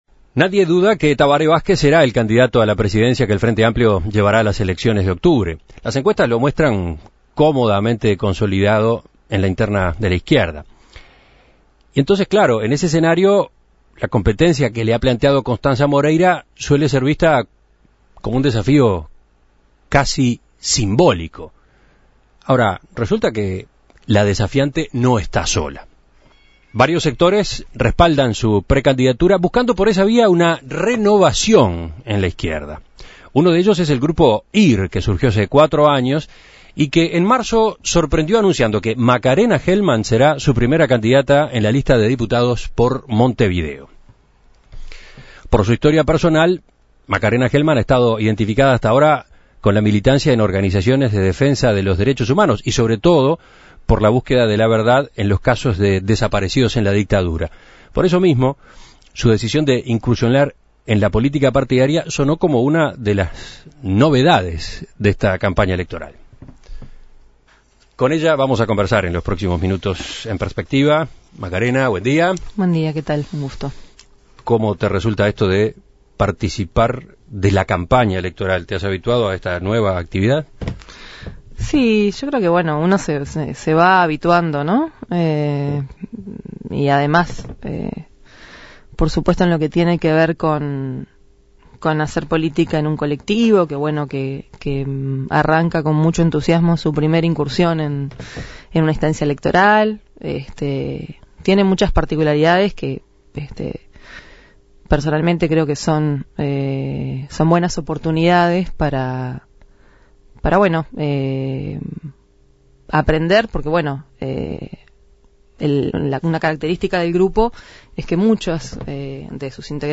En el marco de una nueva conmemoración de la Marcha del Silencio En Perspectiva entrevistó a Gelman.